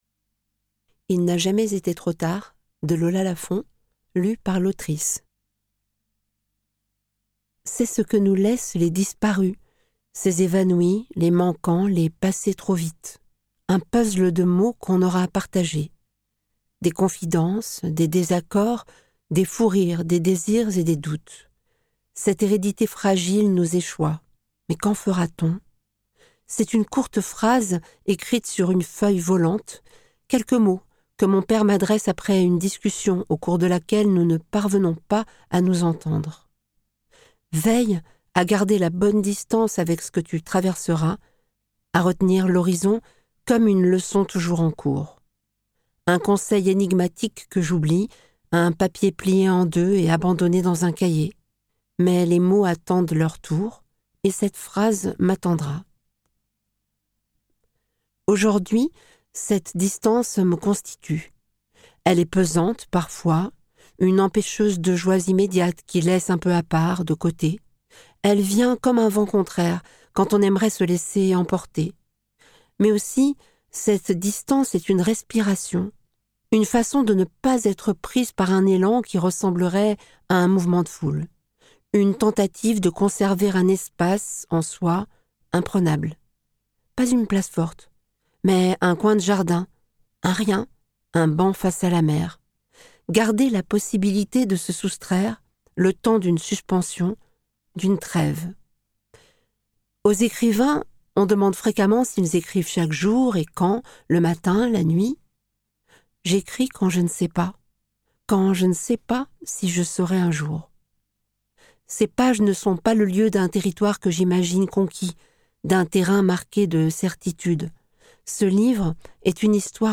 Diffusion distribution ebook et livre audio - Catalogue livres numériques
Lire un extrait - Il n'a jamais été trop tard de Lola Lafon